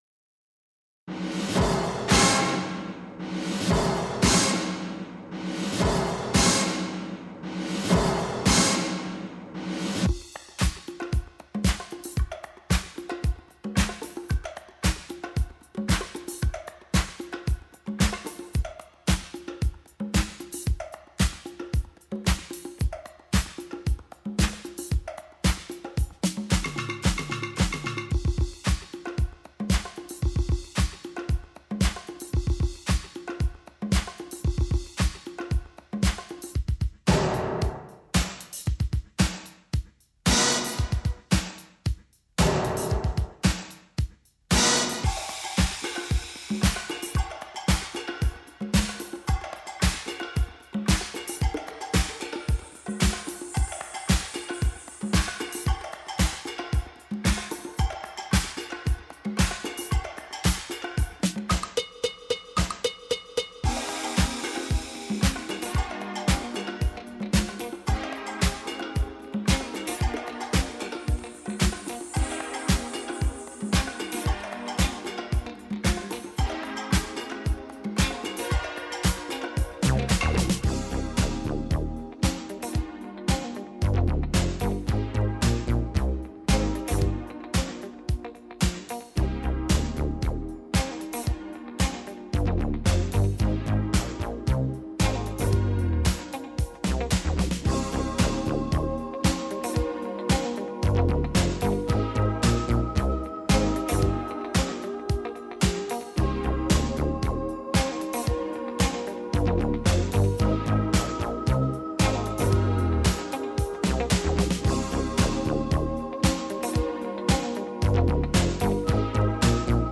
Filed under funk, travel